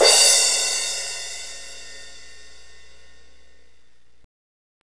Index of /90_sSampleCDs/Northstar - Drumscapes Roland/DRM_Hip-Hop_Rap/CYM_H_H Cymbalsx